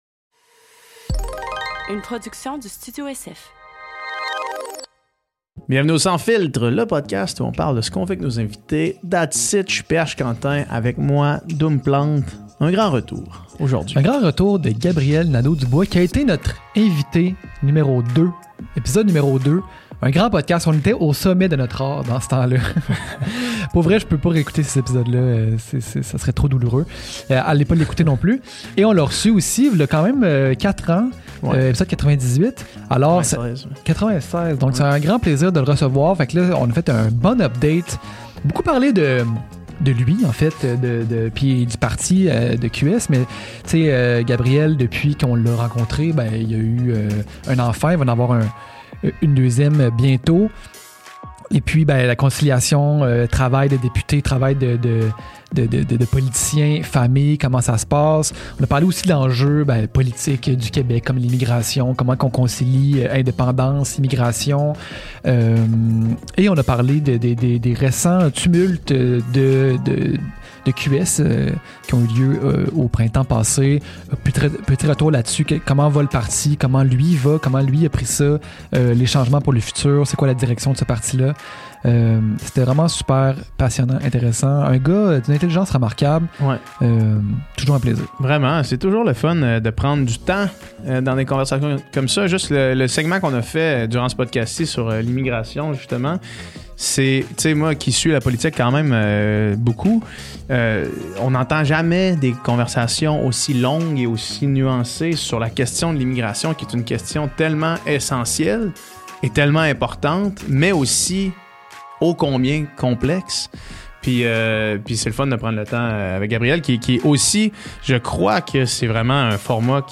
Cette semaine sur le podcast, on reçoit pour la 3e fois le chef de Québec Solidaire, Gabriel Nadeau-Dubois. On fait une mise à jours sur sa vie personnelle et on jase des récents développements au sein de QS.